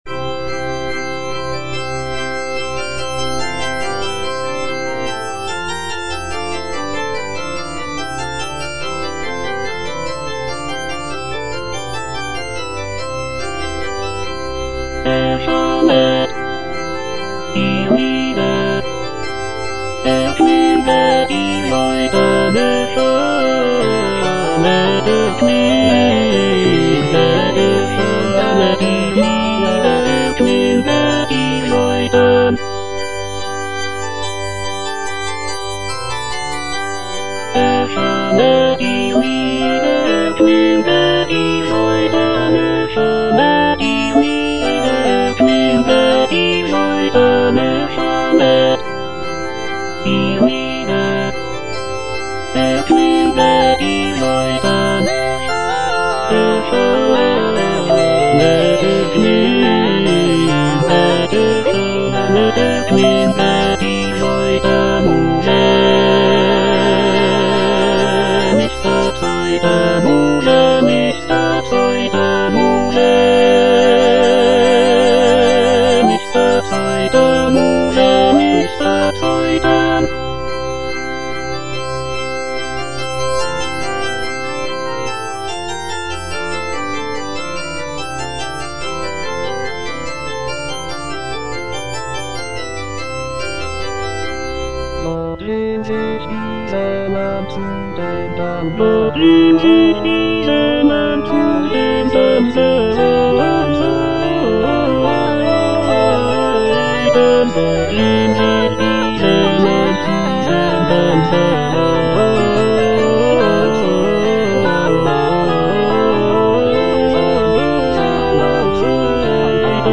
J.S. BACH - CANTATA "ERSCHALLET, IHR LIEDER" BWV172 (EDITION 2) Erschallet, ihr Lieder - Tenor (Emphasised voice and other voices) Ads stop: auto-stop
It features a jubilant opening chorus, expressive arias, and intricate chorales. The text celebrates the coming of the Holy Spirit and the birth of the Christian Church. The music is characterized by its lively rhythms, rich harmonies, and intricate counterpoint.